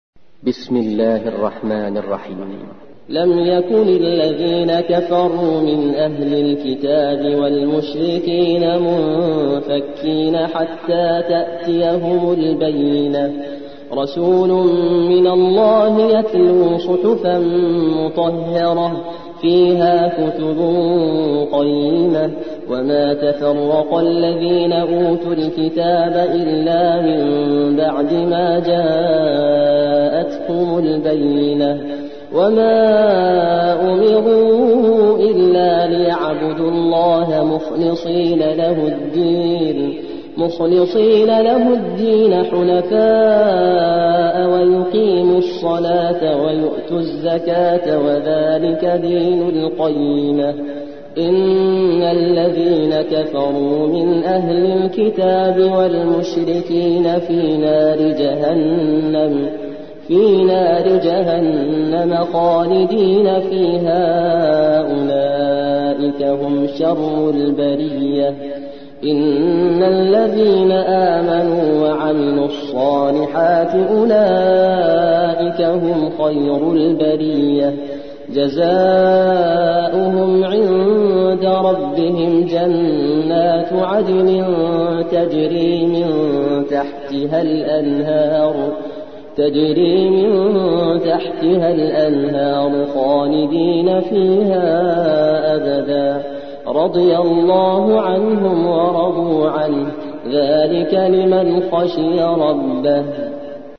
98. سورة البينة / القارئ